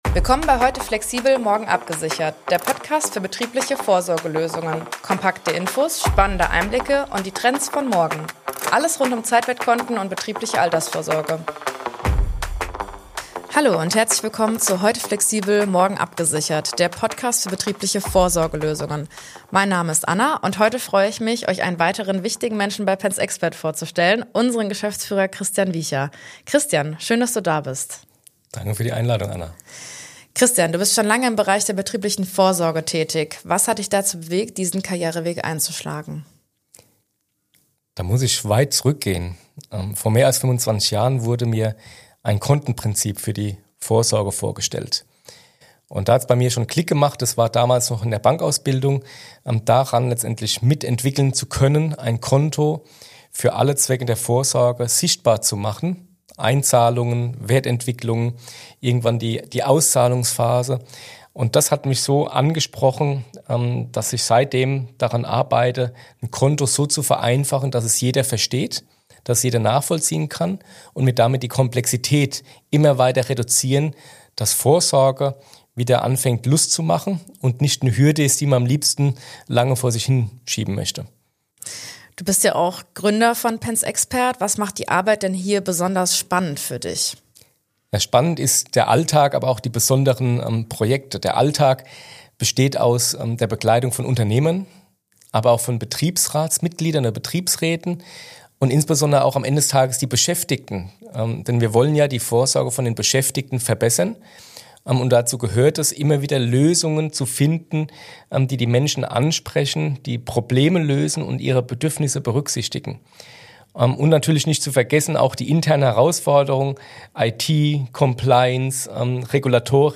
Ein Gespräch über einfache Vorsorge, Fachkräftemangel, Kontenlösungen – und warum Digitalisierung kein Selbstzweck ist.